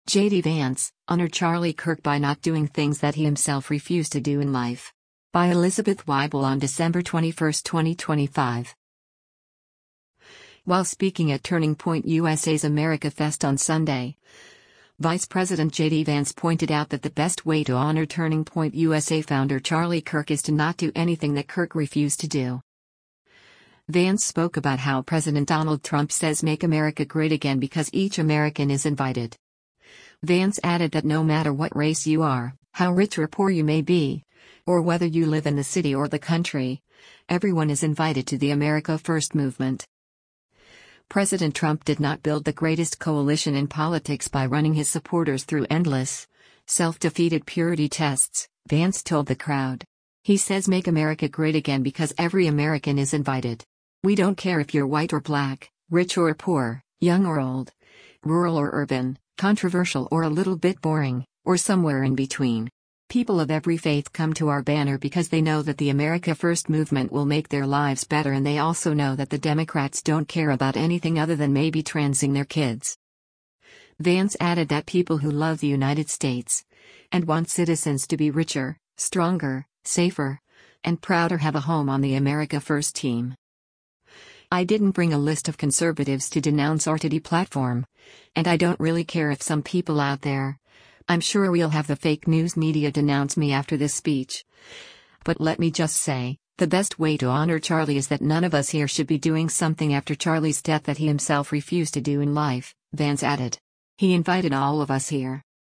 While speaking at Turning Point USA’s AmericaFest on Sunday, Vice President JD Vance pointed out that the “best way” to honor Turning Point USA Founder Charlie Kirk is to not do anything that Kirk “refused to do.”